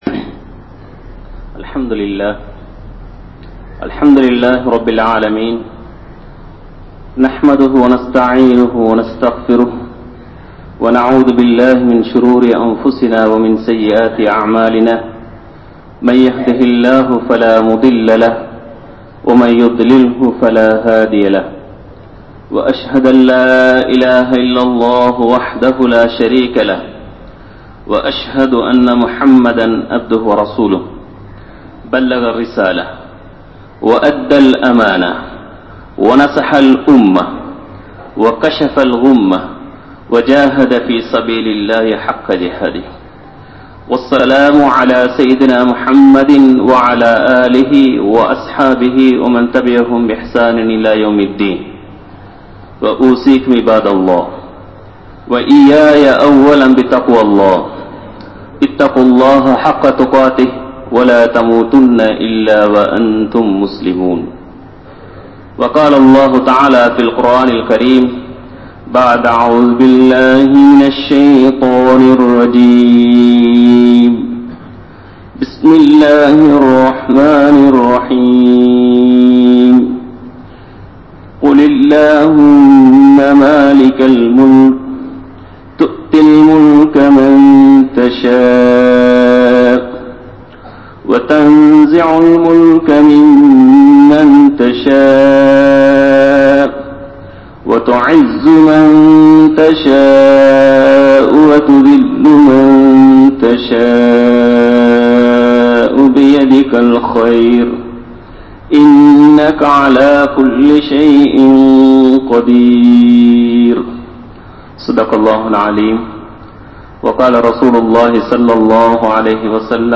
Nabi(SAW)Avarhalin Ganniyam (நபி(ஸல்)அவர்களின் கண்ணியம்) | Audio Bayans | All Ceylon Muslim Youth Community | Addalaichenai
Grand Jumua Masjith